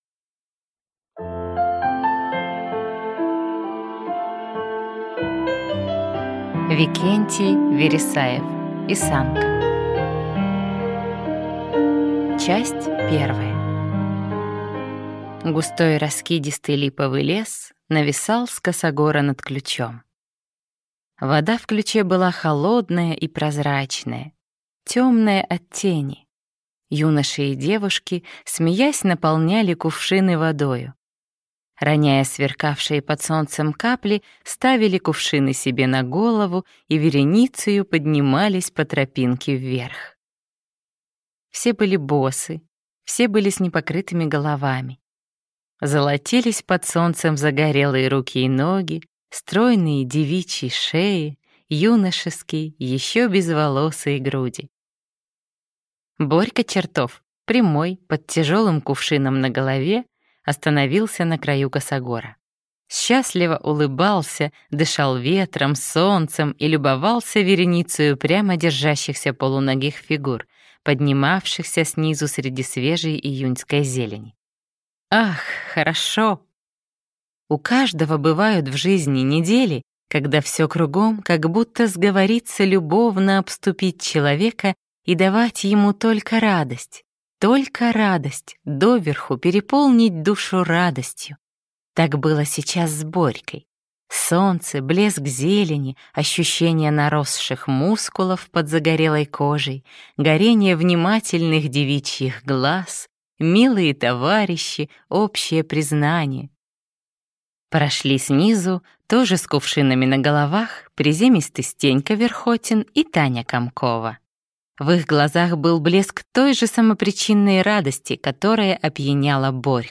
Аудиокнига Исанка | Библиотека аудиокниг